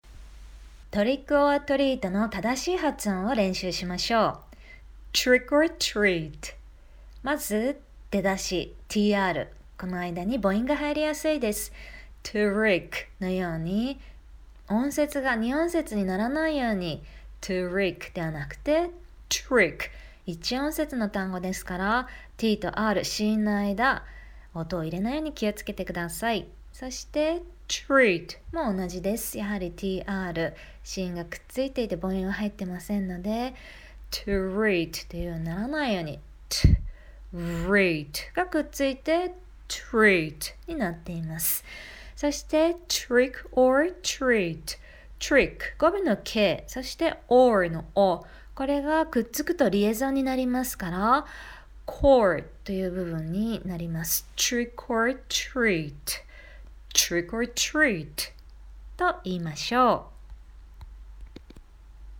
発音のヒント
1. trick、treatは、tの後にの母音を入れないように気をつけましょう。
2. trickのiの部分は、エに近いイ。下あごと舌を少しリラックスさせるとエに近いイになります。
3. treatのeaの部分は、pitch change（音の高低）が出ます。イ～と揺らしてみましょう。
4. trick orのkの音と、orのoの部分をリエゾンすると、よりネイティブな響きになります。